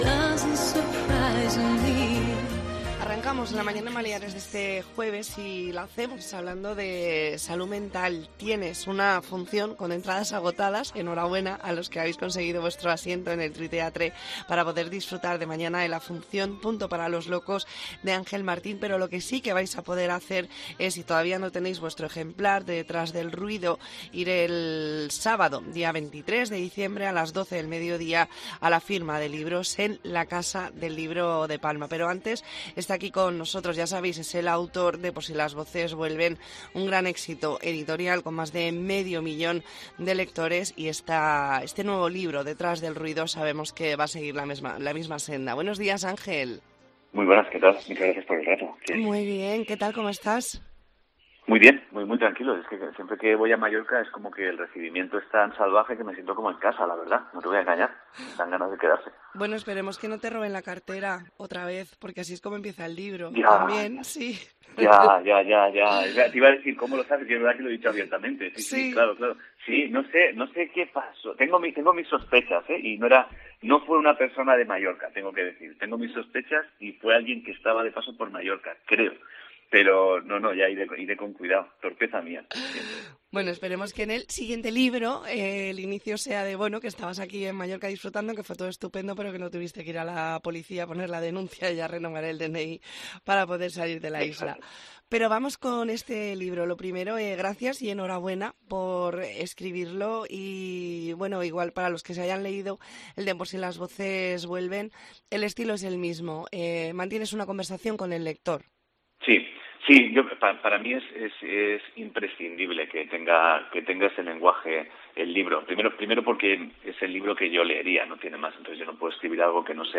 Entrevista en La Mañana en COPE Más Mallorca, jueves 21 de diciembre de 2023.